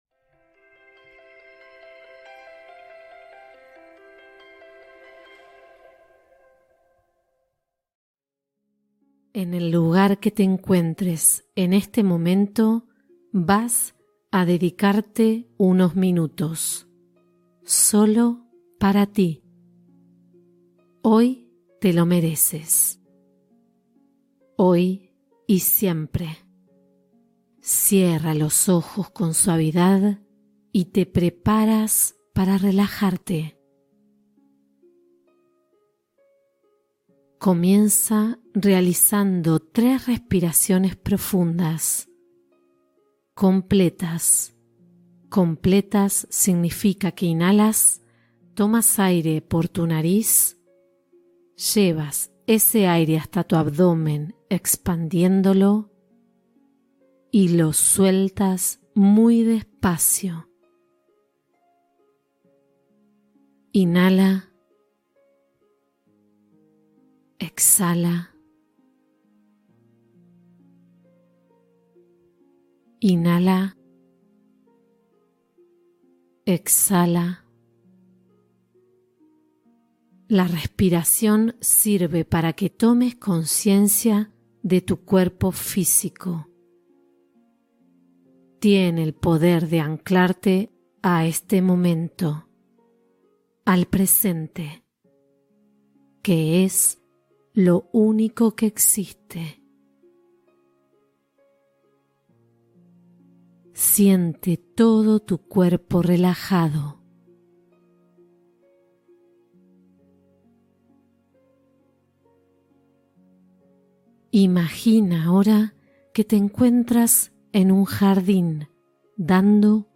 Meditación Profunda de Presencia Interior